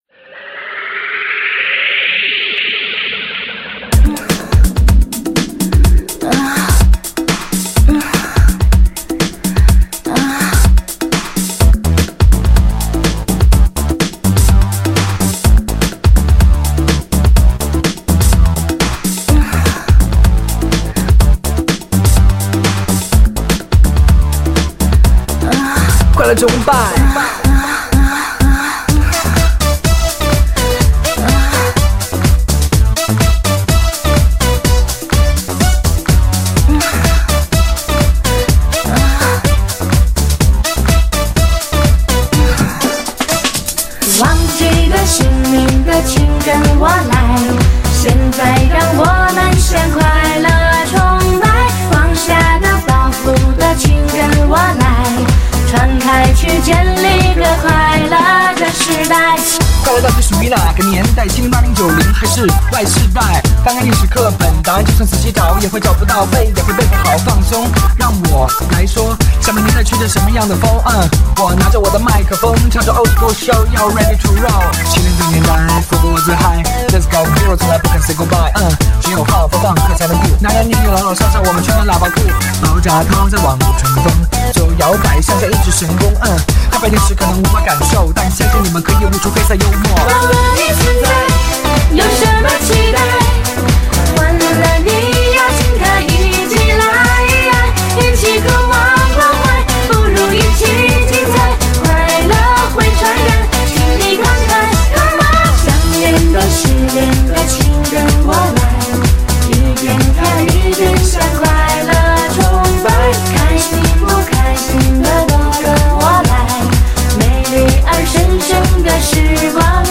狂飙的舞曲
强烈气流冲击，无法自拨的强劲震憾，电浪突袭，电光火石狂野纵情，无边快乐蔓延……